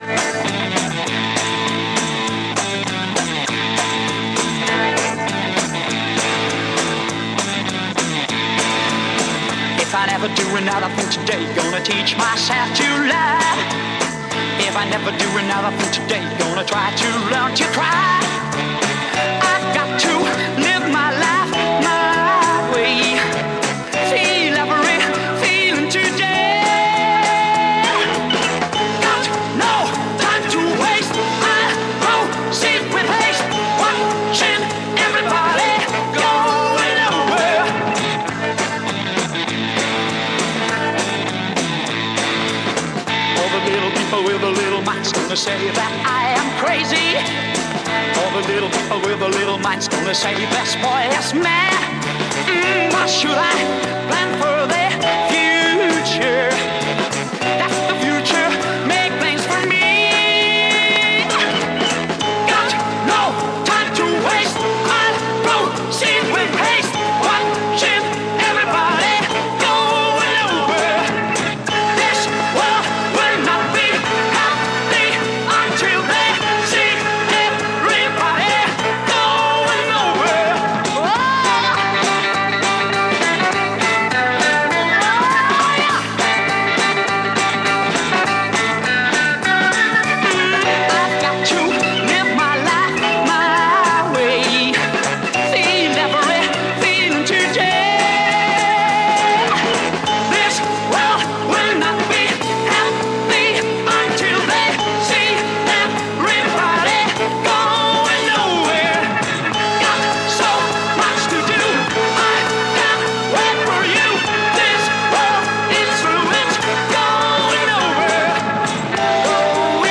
Spanish Freakbeat Mod French EP